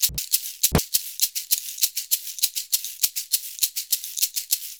100SHAK04.wav